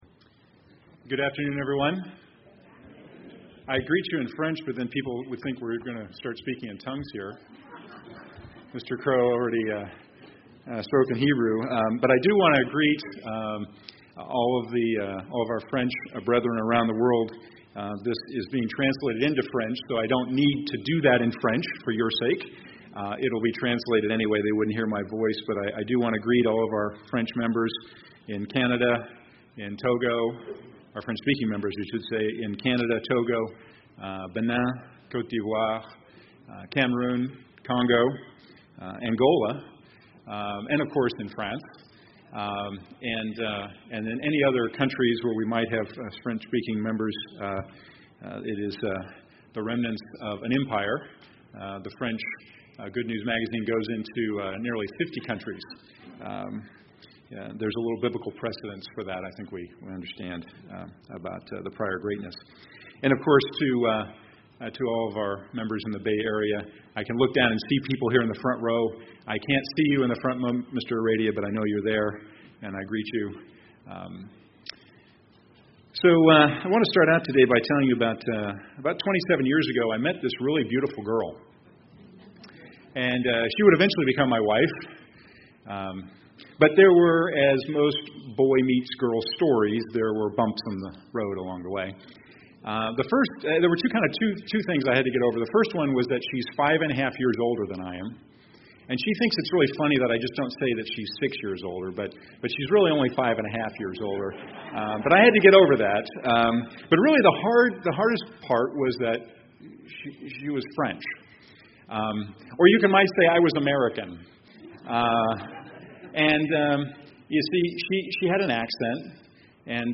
Split Sermon given during the Sabbath at the General Conference of Elders meeting.